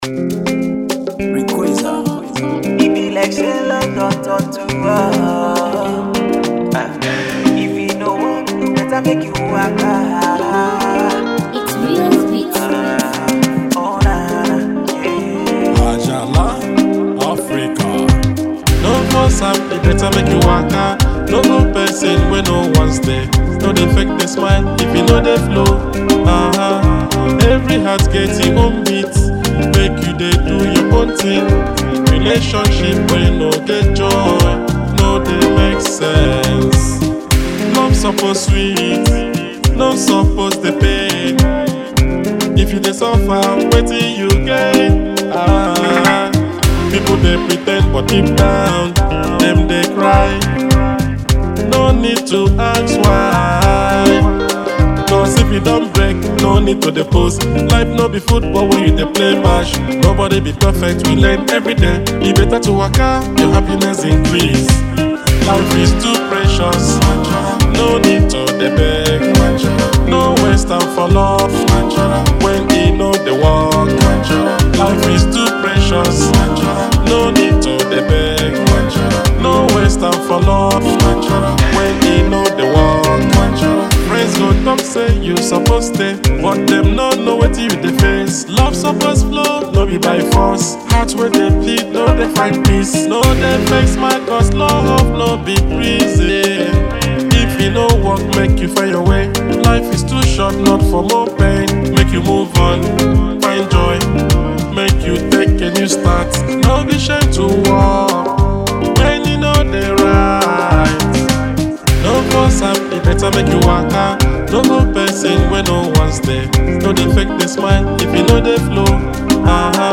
High-life
accompanied with serenading sounds and soothing vocals